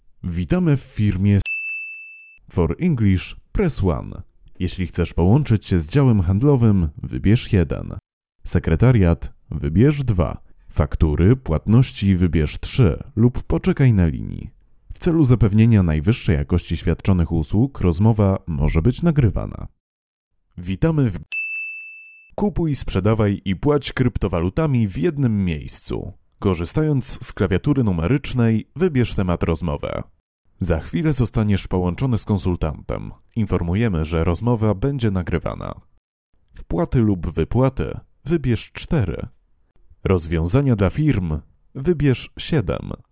Samples of recordings of HaloNet speakers below (wave files):
man's voice 3
glos_meski_3.wav